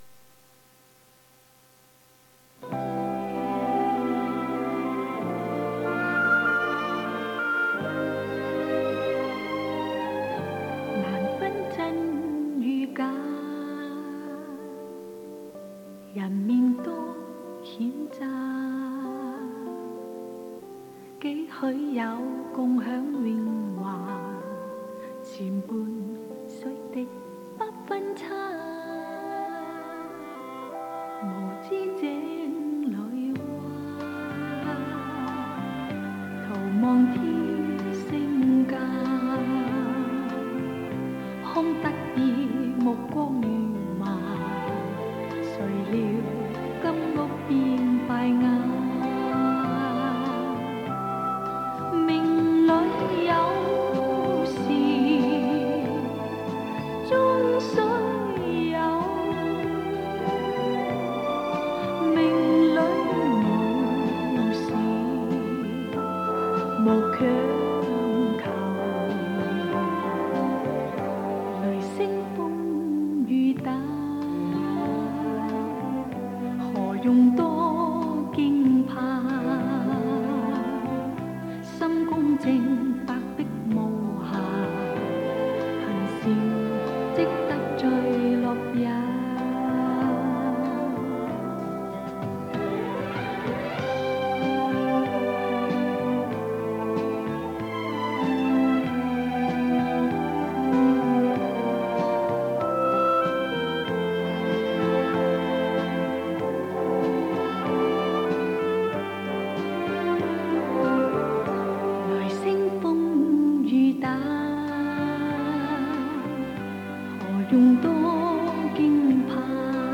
磁带音质温暖
音质很不错，谢谢